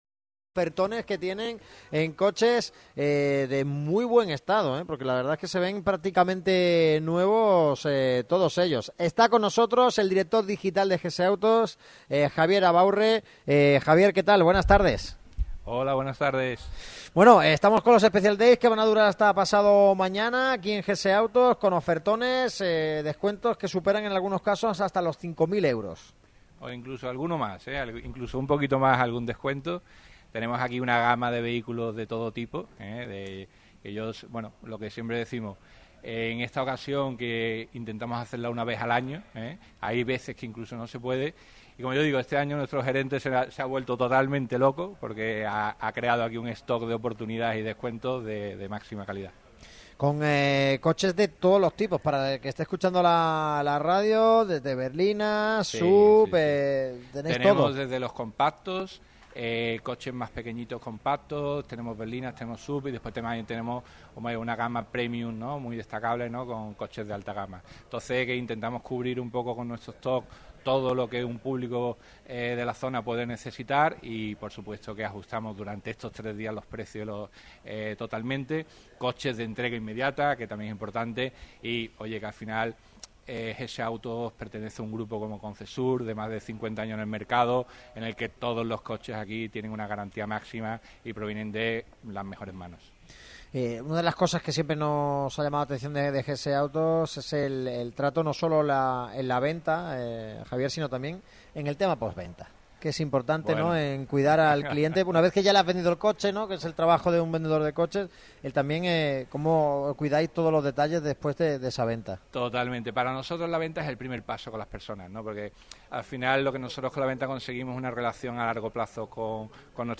Este martes el equipo de Radio MARCA Málaga ha visitado las instalaciones de GS Autos en el Polígono de Santa Bárbara C/ Hnos Lumiere 17, donde están de oferta.